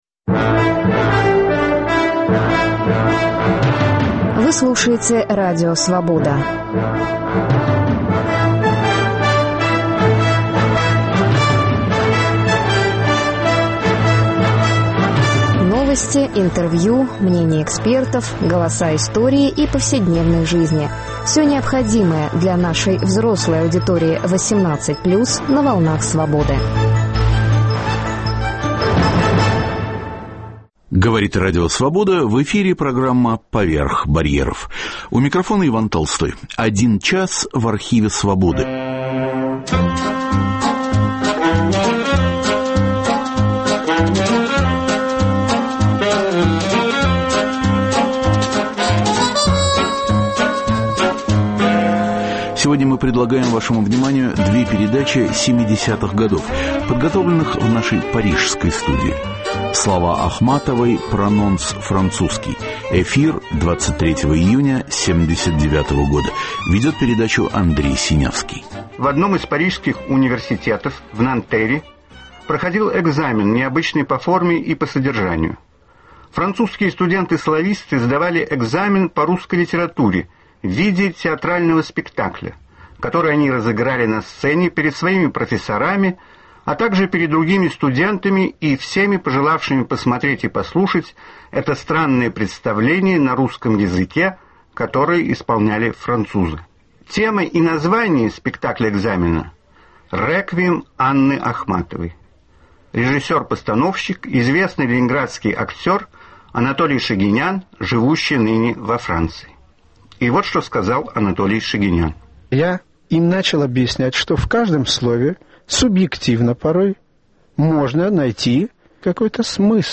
Один час в архиве свободы. Две передачи 70-х годов, подготовленных в нашей парижской студии.